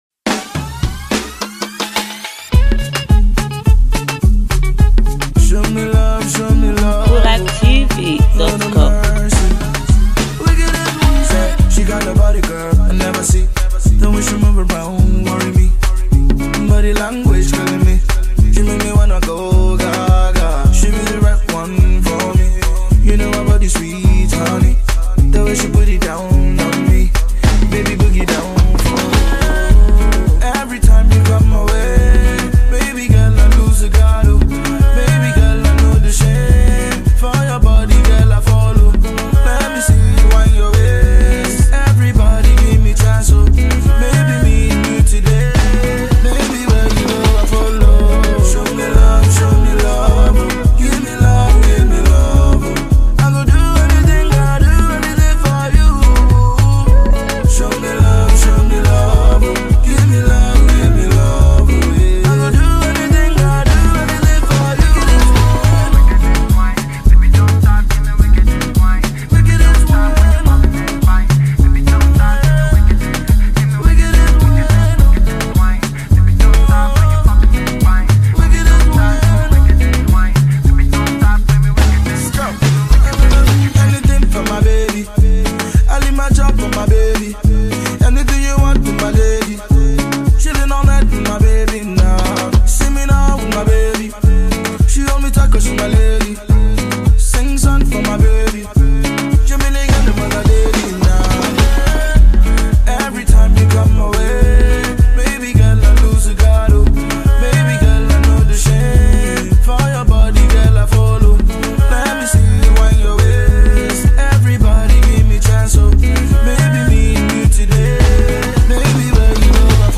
Afro-beat banger